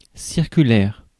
Ääntäminen
Ääntäminen France (Paris): IPA: [siʁ.ky.lɛʁ] Haettu sana löytyi näillä lähdekielillä: ranska Käännös Ääninäyte Substantiivit 1. circular US 2. circular letter 3. flyer Adjektiivit 4. circular US Suku: f .